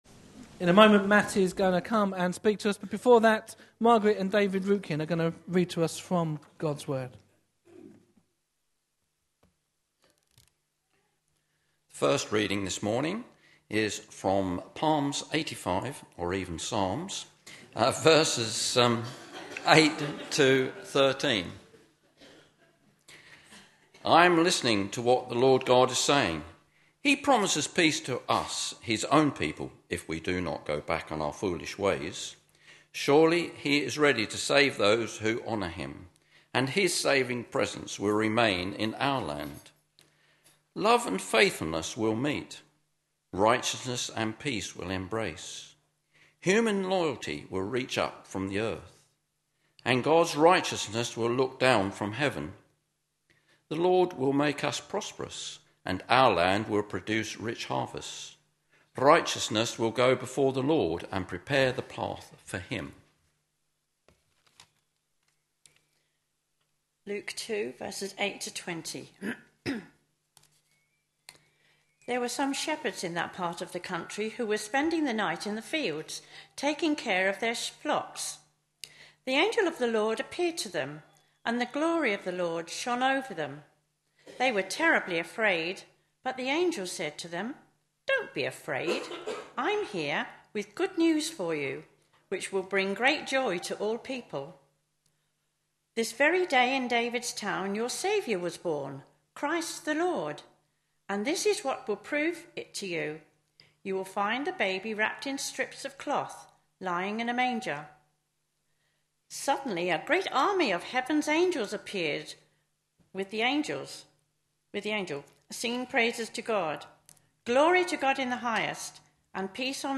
A sermon preached on 21st December, 2014, as part of our Advent 2014. series.